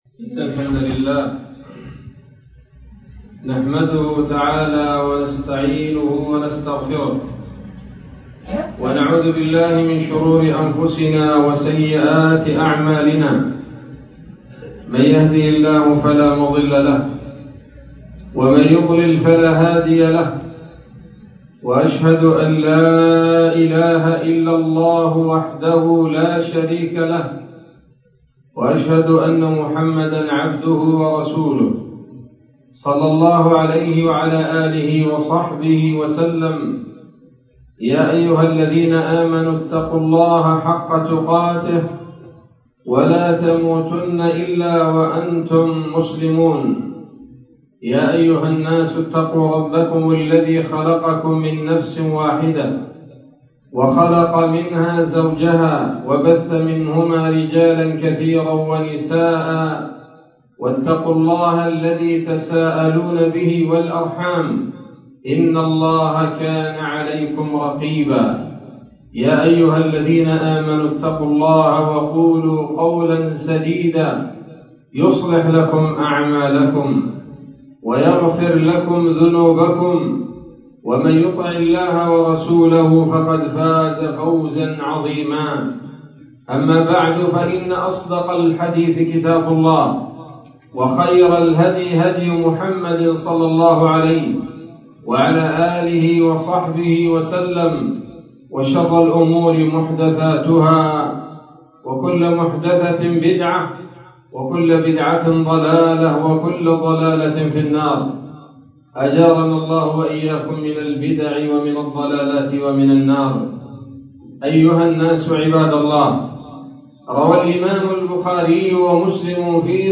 خطبة جمعة بعنوان: (( فضائل يوم عرفة )) 3 ذو الحجة 1446 هـ، مسجد الزهراء - الهرم -القاهرة - مصر